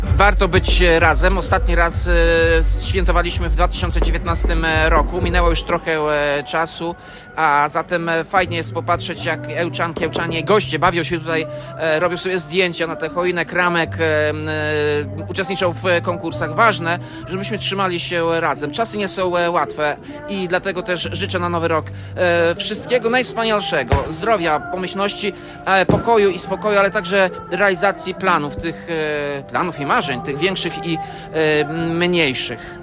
Ełczanie wspólnie powitali Nowy Rok pod chmurką. Po kilku latach przerwy wrócił Miejski Sylwester.
Tuż przed północą życzenia mieszkańcom miasta i gościom złożył Tomasz Andrukiewicz, prezydent Ełku.